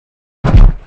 sounds / monsters / boar / landing_0.ogg
landing_0.ogg